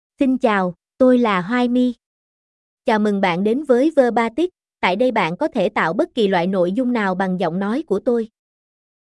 HoaiMyFemale Vietnamese AI voice
HoaiMy is a female AI voice for Vietnamese (Vietnam).
Voice sample
Listen to HoaiMy's female Vietnamese voice.
Female